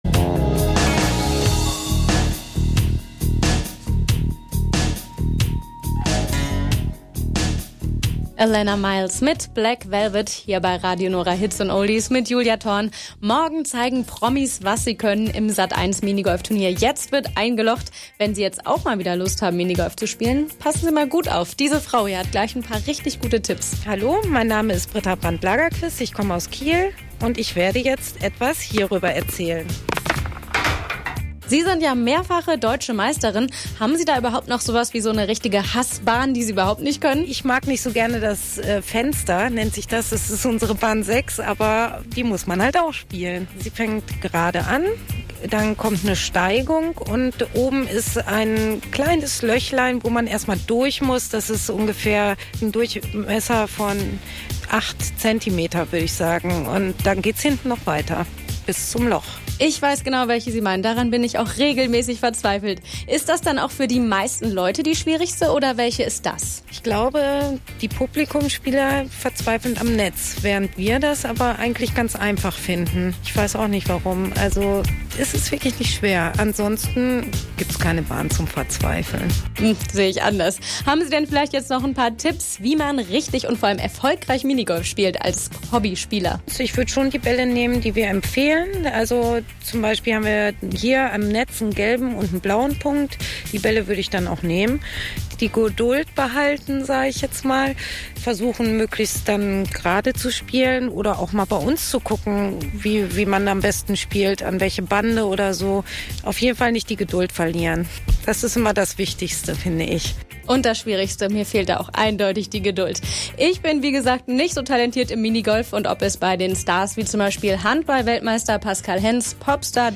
Einen Tag vor der "Sat.1" - Show "Jetzt wird eingelocht!" riefen viele große Rundfunkanstalten Minigolfer ihrer Region an, fragten sie zur Ausstrahlung der Sendung und zum Thema Minigolf allgemein.